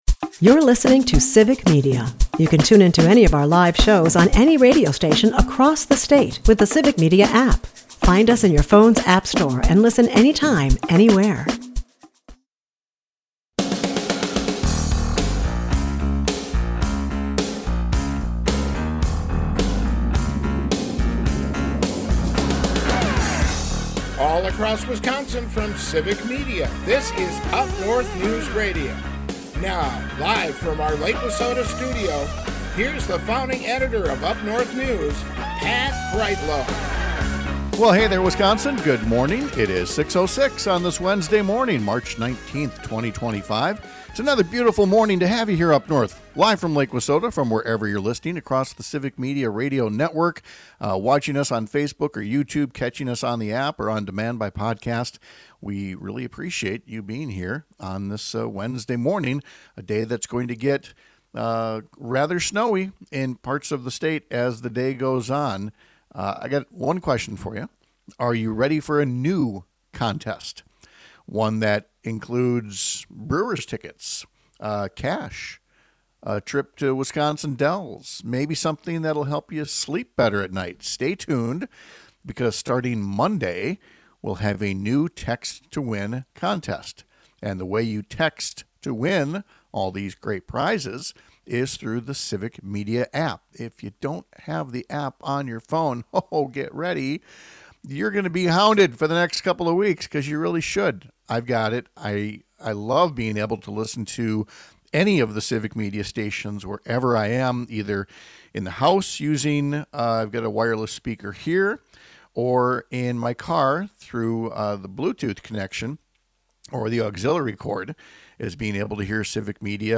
UpNorthNews with Pat Kreitlow airs on several stations across the Civic Media radio network, Monday through Friday from 6-8 am.